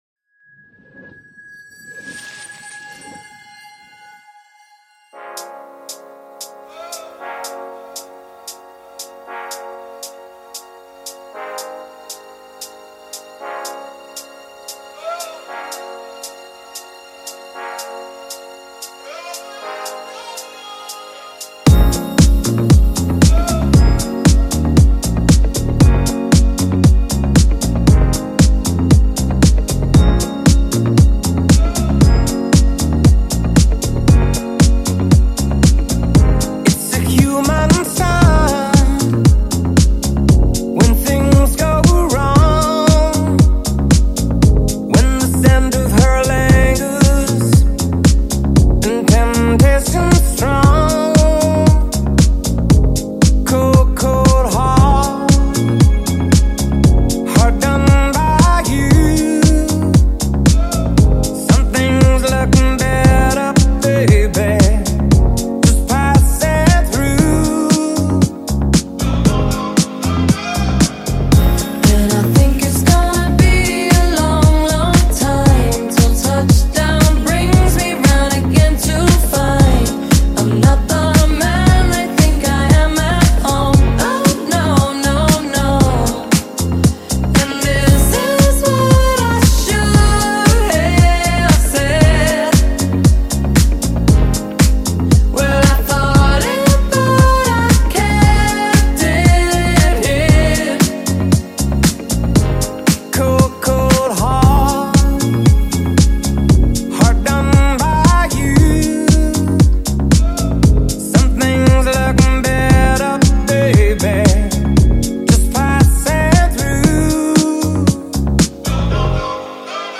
Dance Para Ouvir: Clik na Musica.